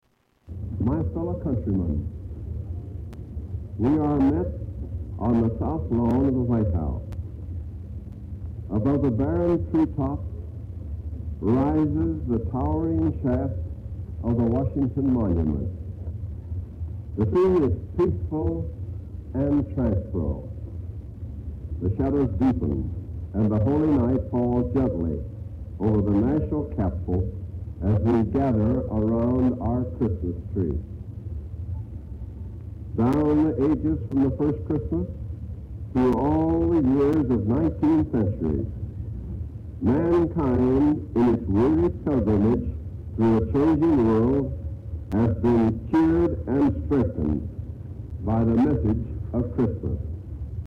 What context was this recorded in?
December 24, 1947 - Lighting of the White House Christmas Tree